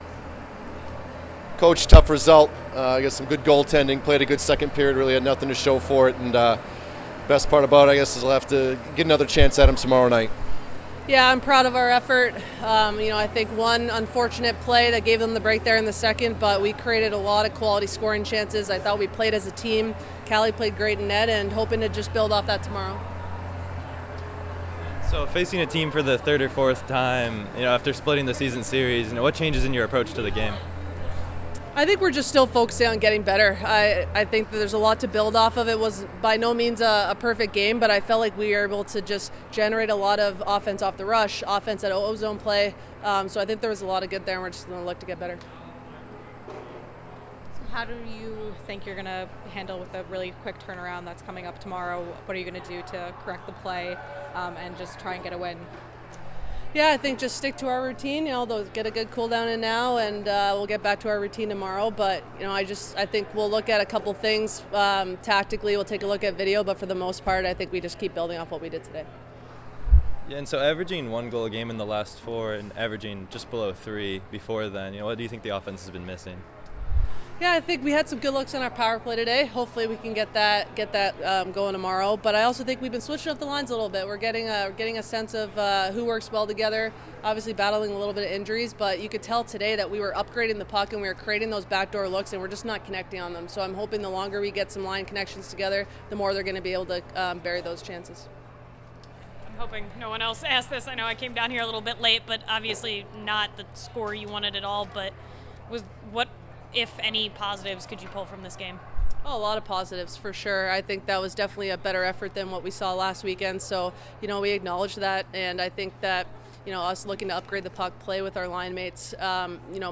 Boston College Postgame Interview (2-2-24)